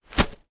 playerGroundHit.wav